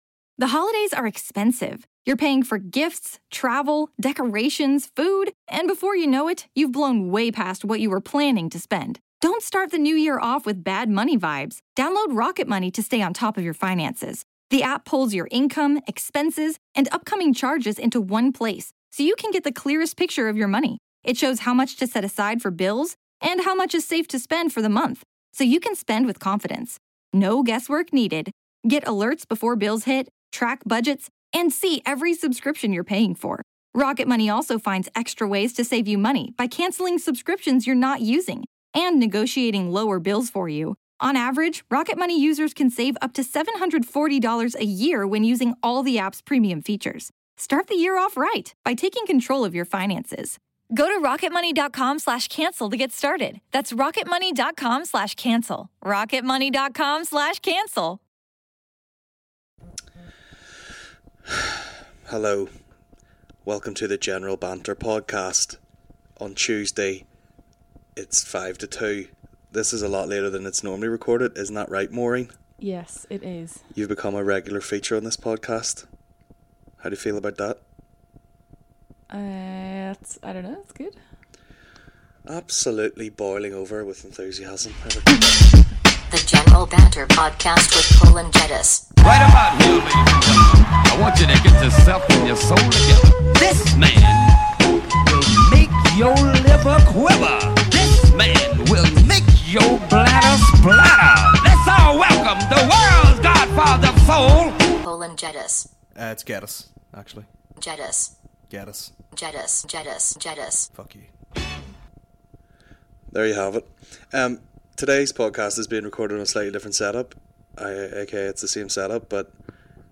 Comedy podcast